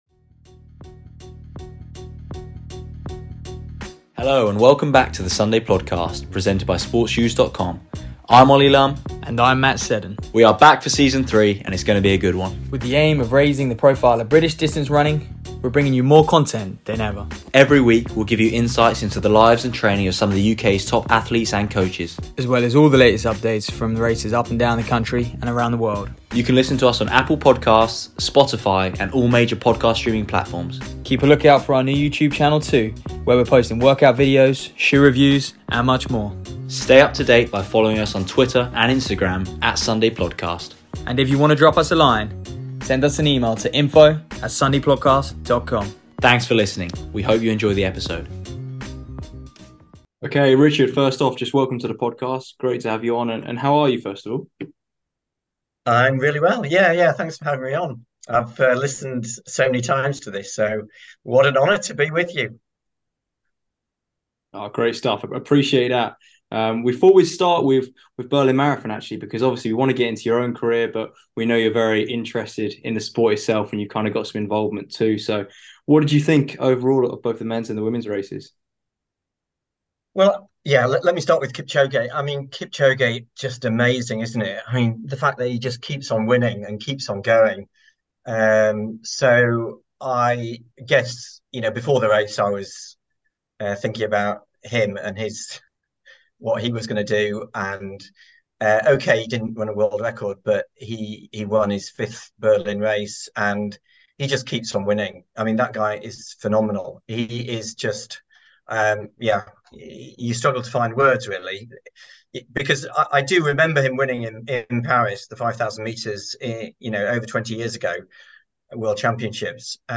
For this week’s podcast, we spoke to two-time Olympian, Richard Nerurkar. Richard represented Great Britain at the Olympics in 1992 over 10,000m and in 1996 over the marathon.